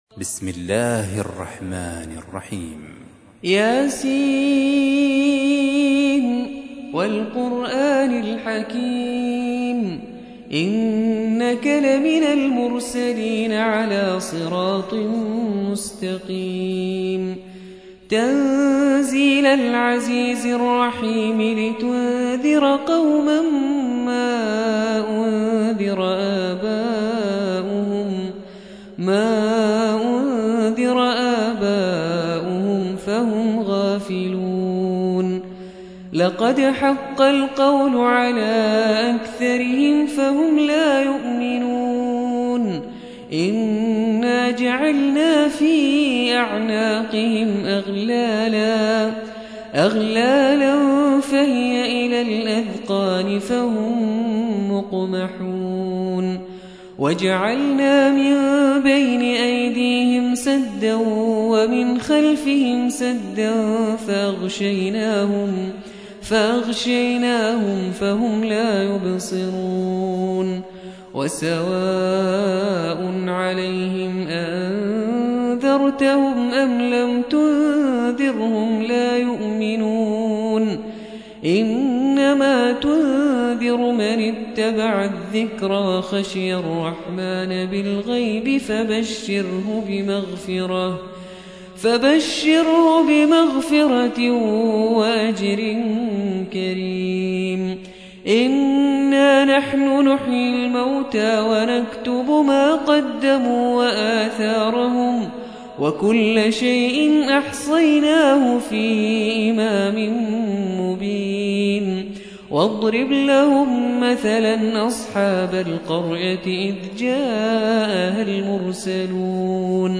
36. سورة يس / القارئ